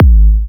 edm-kick-52.wav